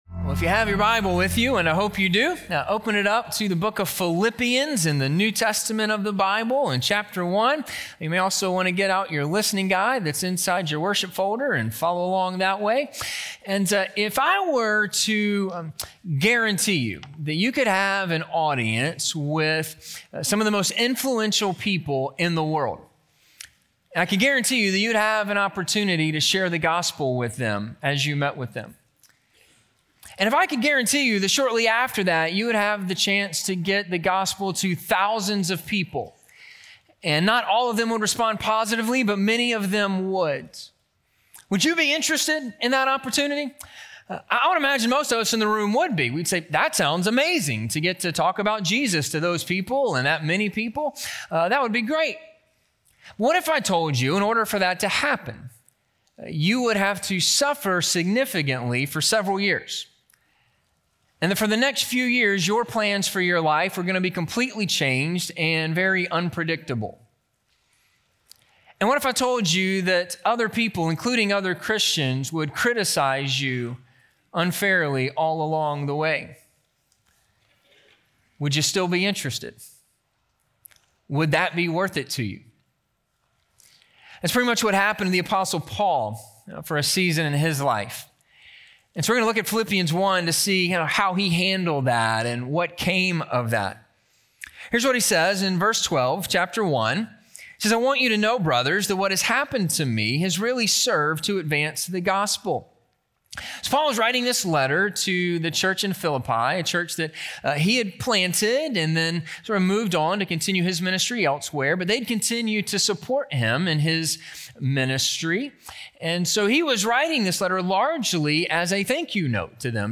A Reason to Rejoice - Sermon - Ingleside Baptist Church